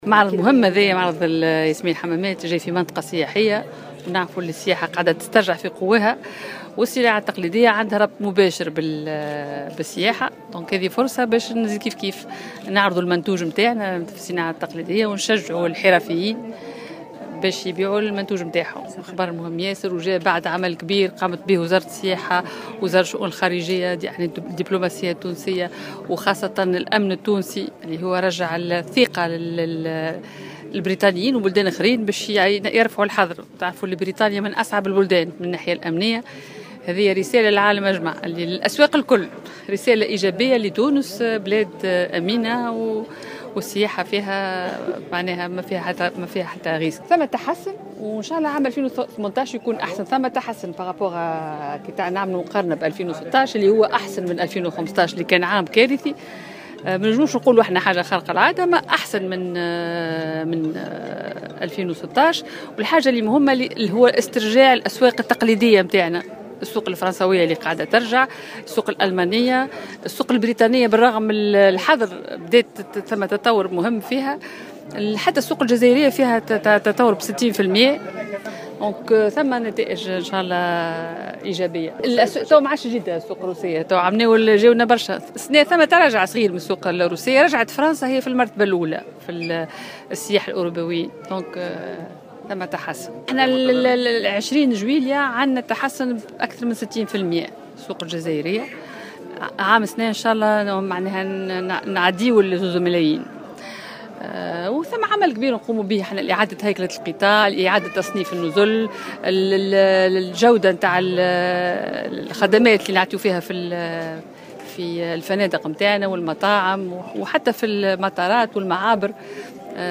وقالت وزيرة السياحة، في تصريح لمراسلة الجوهرة أف أم، على هامش افتتاح معرض الصناعات التقليدية في دورته الأولى بالمنطقة السياحية ياسمين الحمامات، اليوم الخميس، قالت إن القرار البريطاني يبعث برسالة إيجابية إلى دول العالم مفادها أن تونس دولة آمنة وهو ما سينعكس بالإيجاب على المؤشرات السياحية التي تعرف تحسنا مقارنة بالموسم الماضي.